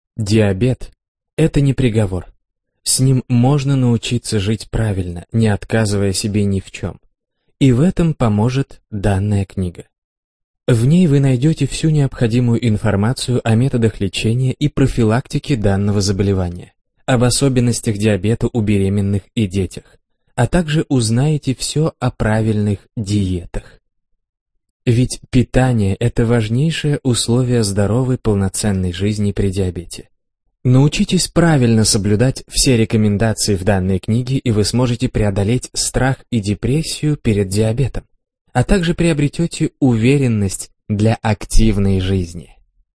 Аудиокнига Диабет. Как победить болезнь | Библиотека аудиокниг